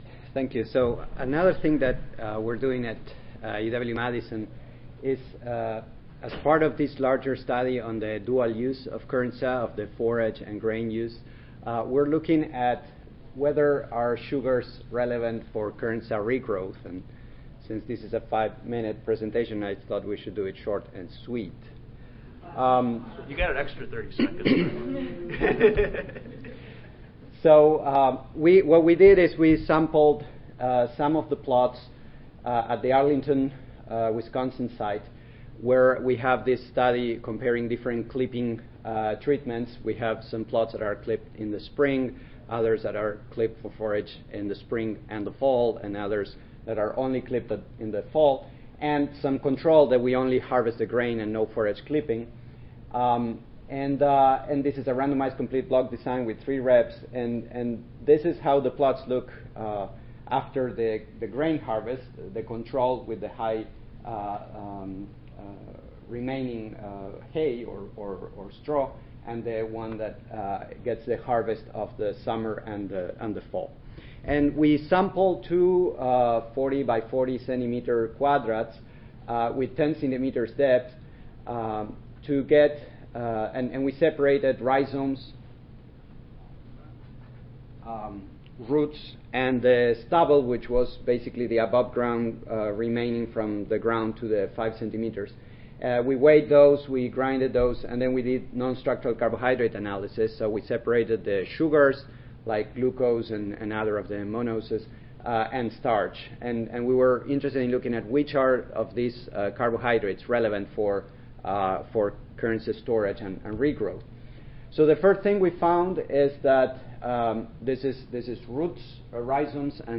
2017 Annual Meeting | Oct. 22-25 | Tampa, FL
This is a general Perennial Grain Development oral session comprised of five minute rapid contributed presentations.
Audio File Recorded Presentation